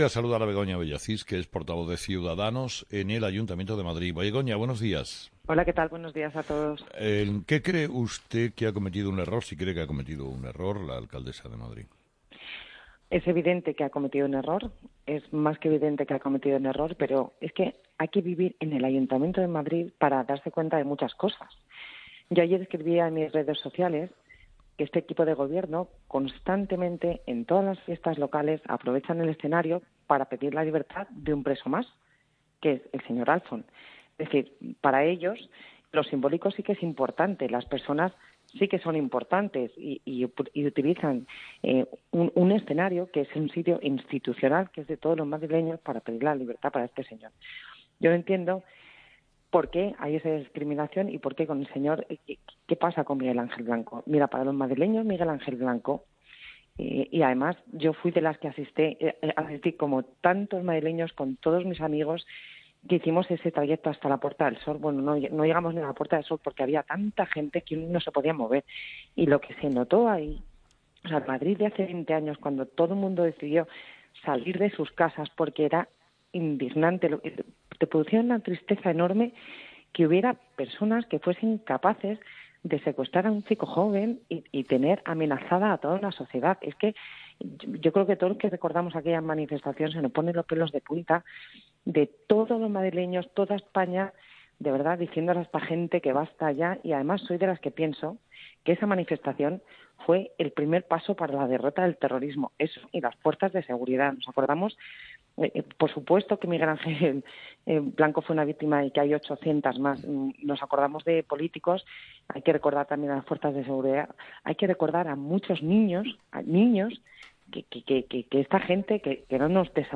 Entrevista a Begoña Villacís COPE